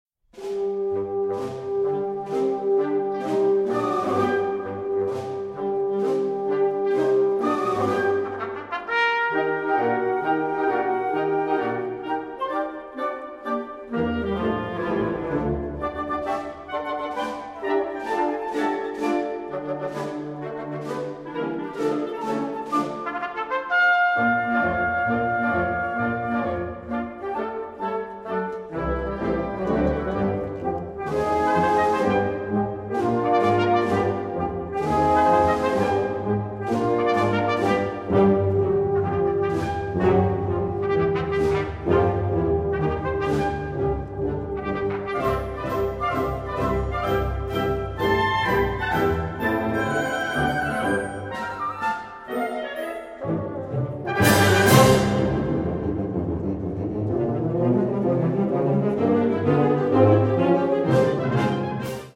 is a march marching soldiers proposes.